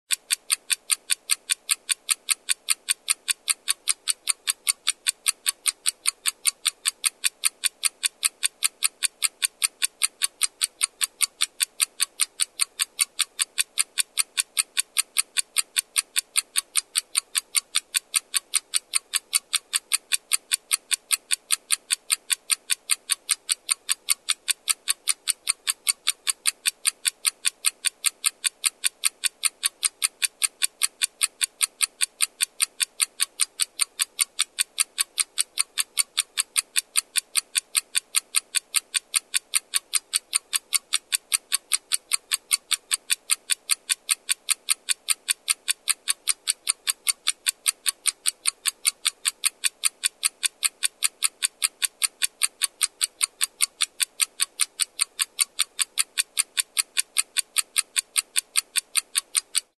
Звуки секундомера
Звук секундомера таймера ровно 60 секунд